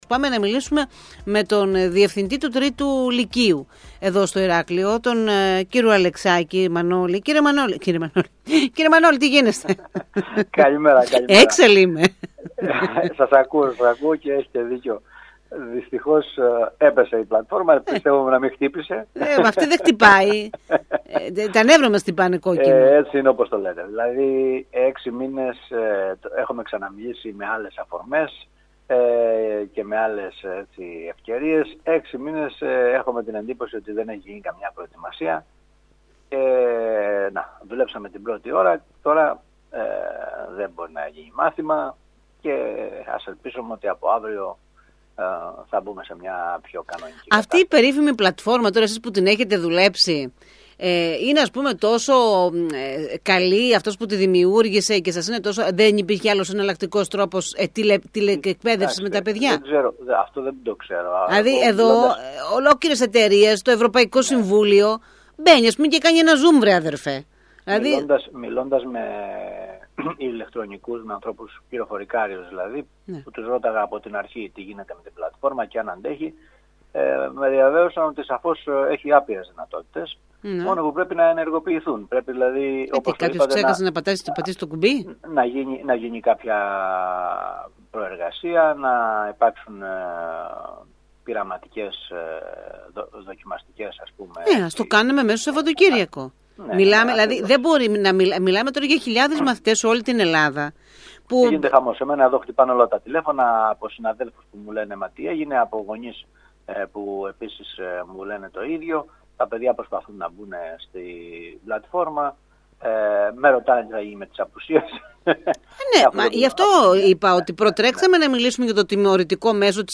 μιλώντας στον ΣΚΑΪ Κρήτης 92.1